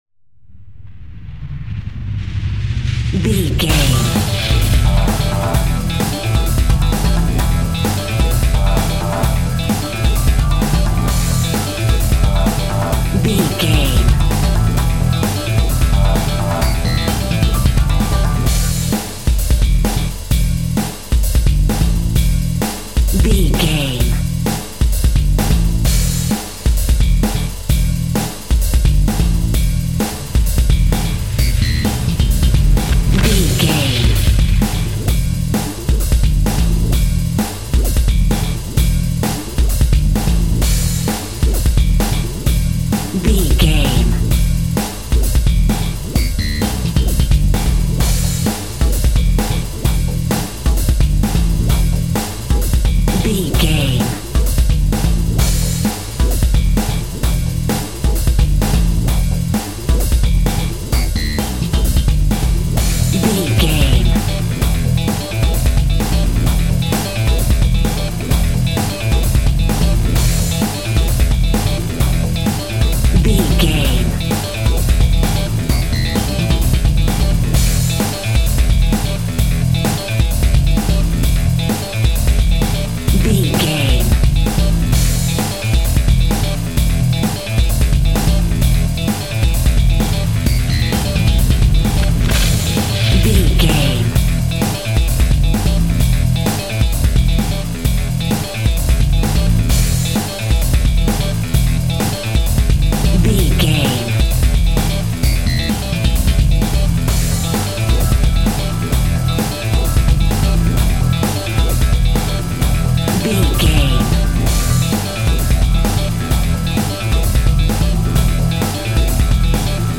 Edgy Breakbeat.
Fast paced
Aeolian/Minor
funky
groovy
energetic
driving
bass guitar
electric guitar
drums
synthesiser
electric piano
pumped up rock
power rock
electronic
synth lead
synth bass